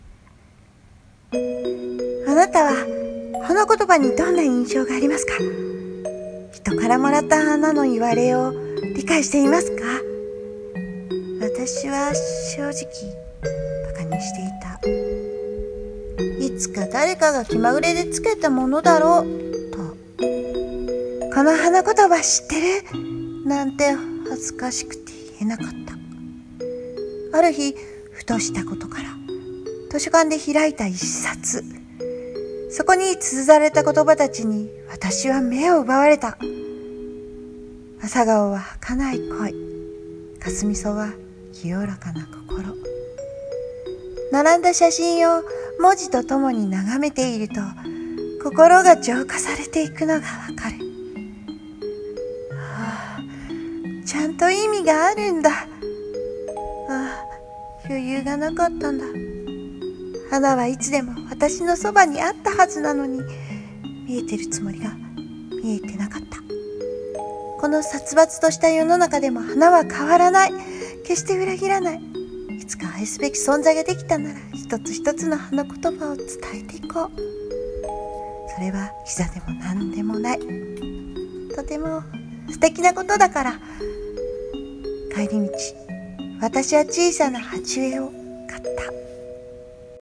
【優しさ系】1人用声劇台本「花言葉」